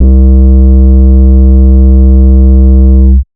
808s
tm_metro_808.wav